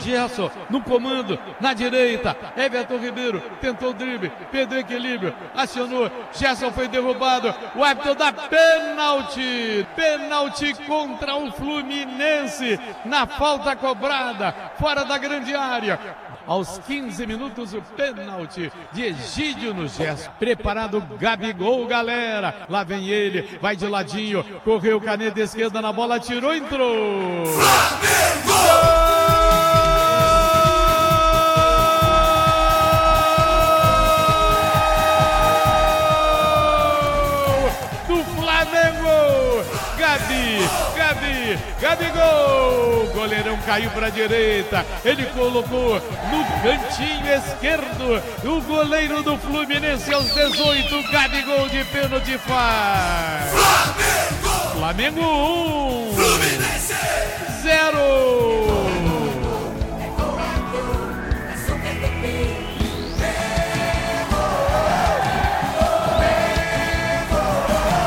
Ouça os gols do empate entre Fluminense e Flamengo com a narração de Garotinho